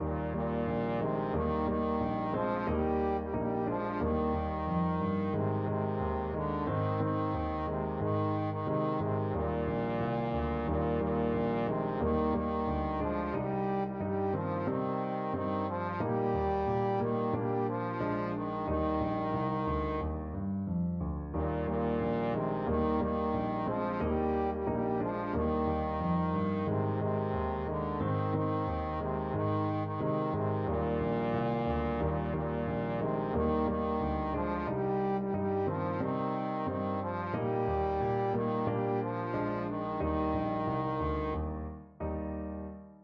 Trombone
Eb major (Sounding Pitch) (View more Eb major Music for Trombone )
Steady two in a bar = c. 90
2/2 (View more 2/2 Music)
Bb3-G4
Traditional (View more Traditional Trombone Music)